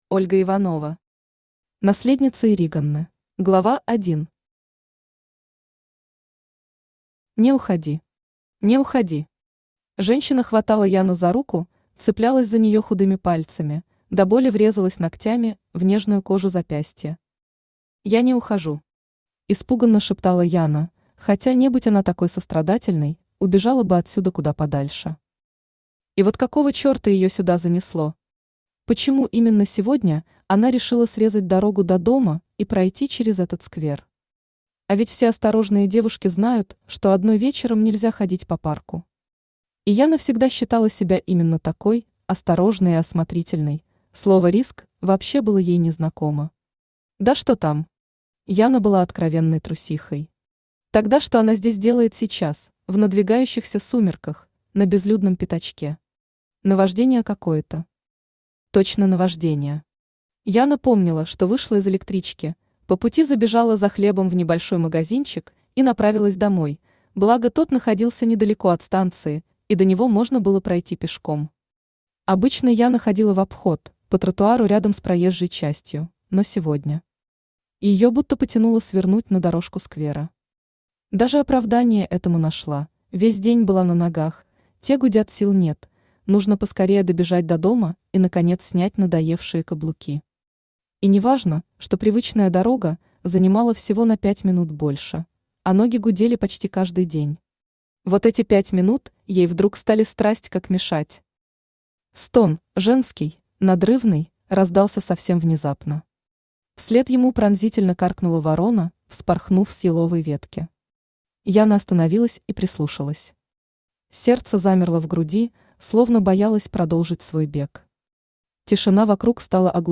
Аудиокнига Наследница Ириганны | Библиотека аудиокниг
Aудиокнига Наследница Ириганны Автор Ольга Дмитриевна Иванова Читает аудиокнигу Авточтец ЛитРес.